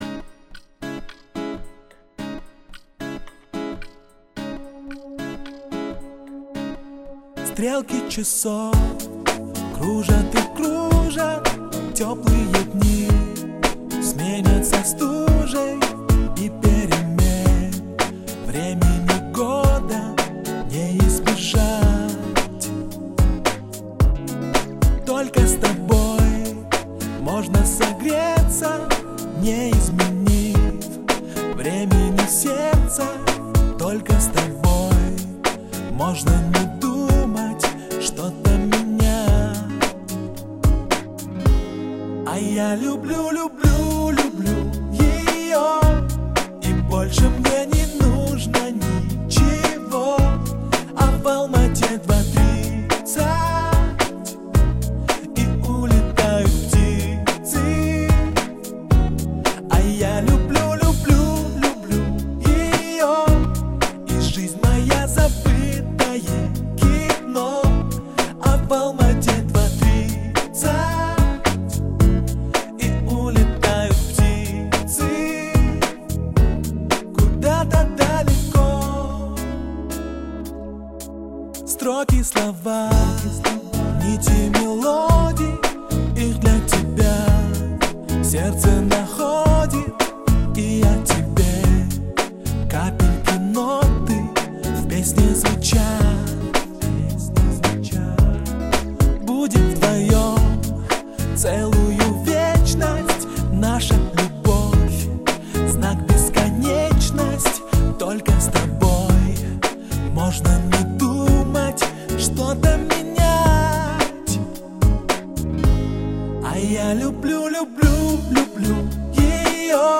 это романтическая песня в жанре поп